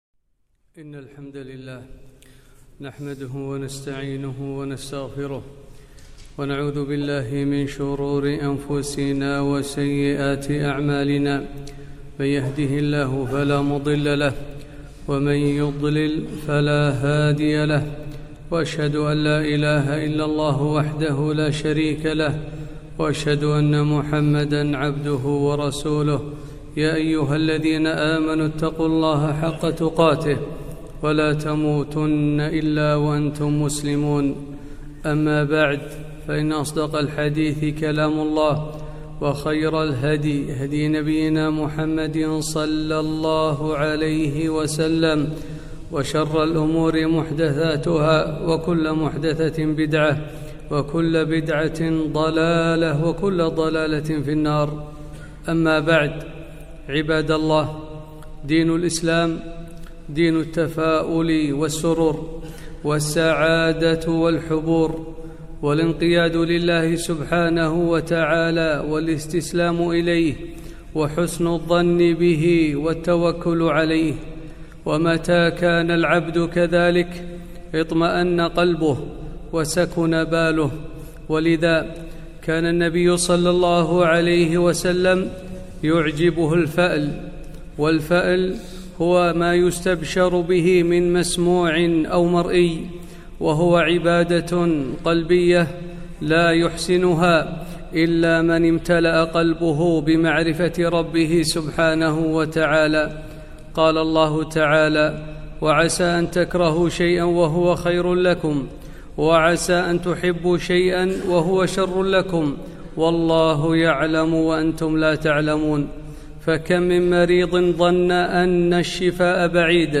خطبة - الإسلام دين التفاؤل